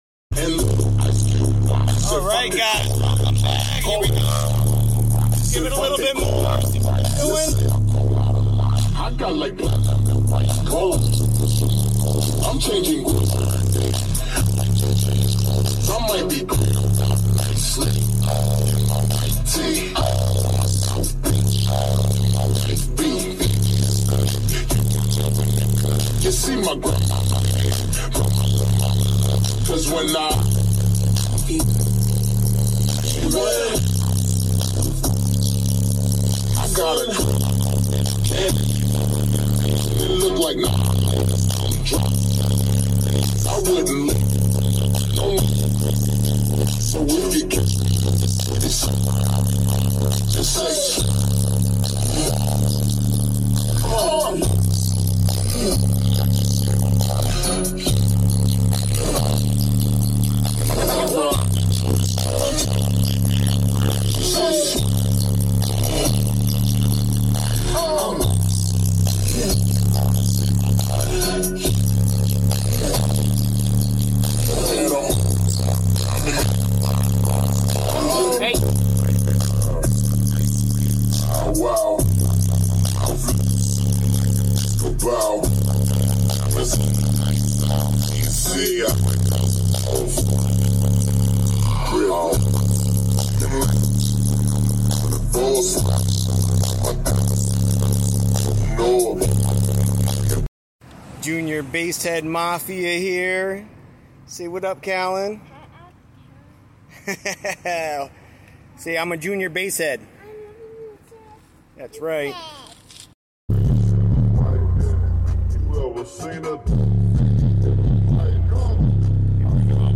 Basshead Garage Wattmeter VS SMD-AMM1 Dyno Test